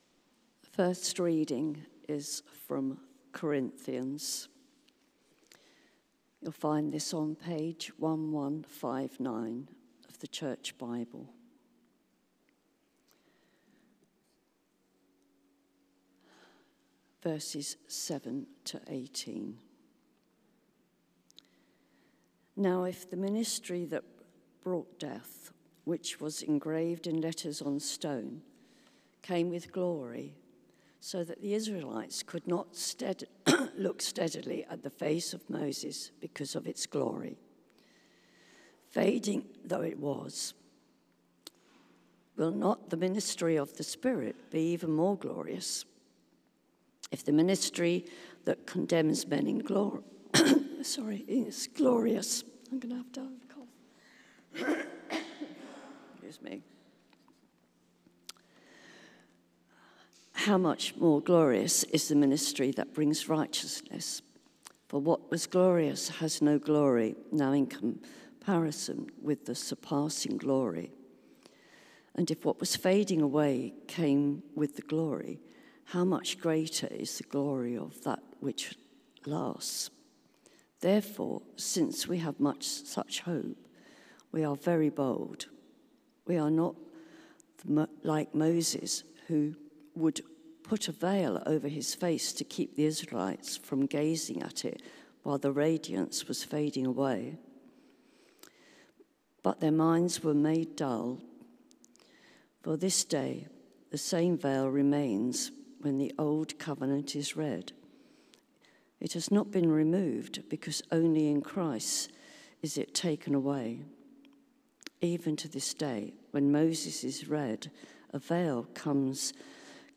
Media for Service (10.45) on Sun 10th Aug 2025